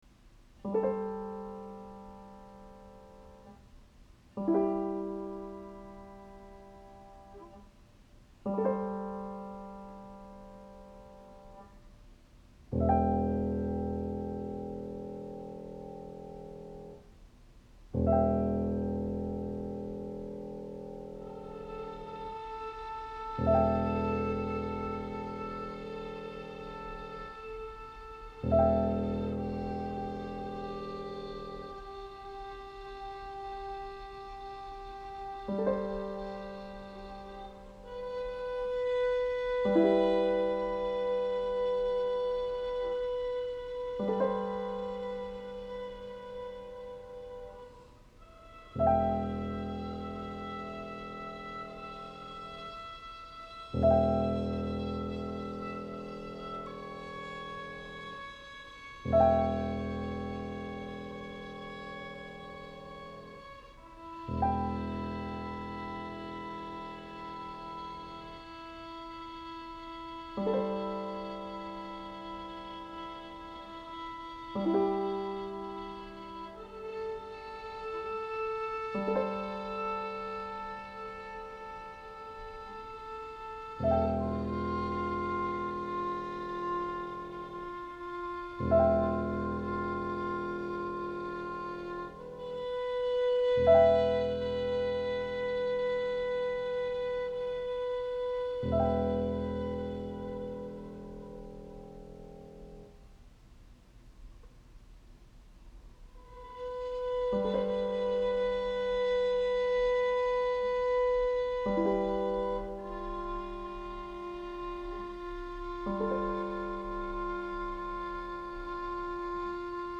violin
clarinet
percussion
trombone
voice
guitar
piano